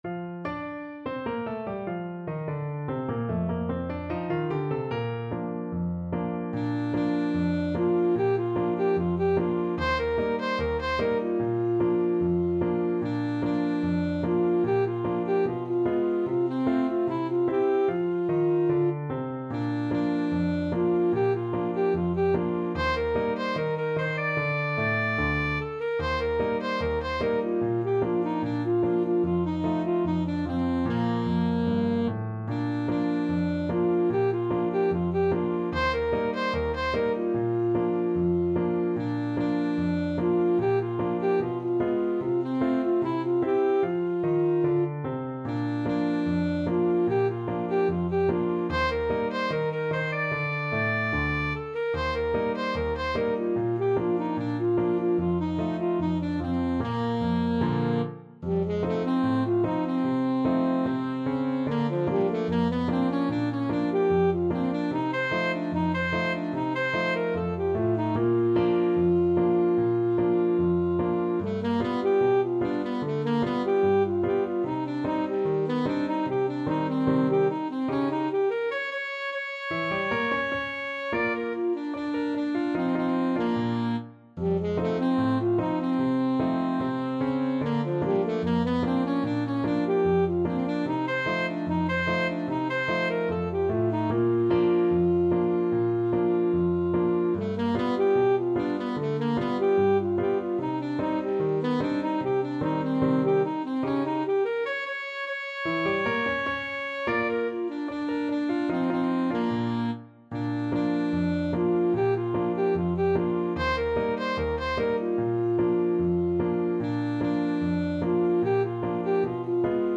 Alto SaxophoneTenor SaxophonePiano
Bb major (Sounding Pitch) (View more Bb major Music for Alto-Tenor-Sax Duet )
Not Fast = 74
2/4 (View more 2/4 Music)
Jazz (View more Jazz Alto-Tenor-Sax Duet Music)